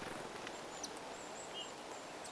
tuollainen tiaisen ääni
tuollainen_tiaisen_aani.mp3